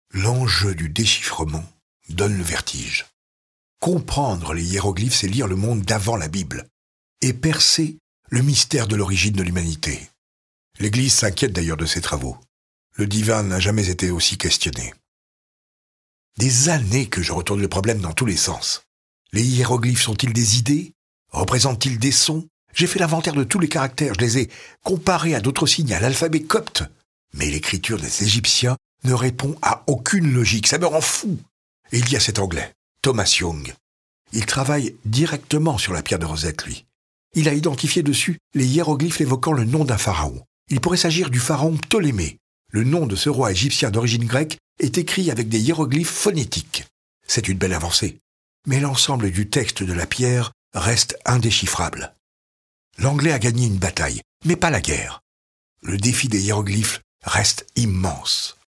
Voix off
voix de Champollion